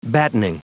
Prononciation du mot battening en anglais (fichier audio)
Prononciation du mot : battening